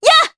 Artemia-Vox_Attack2_jp.wav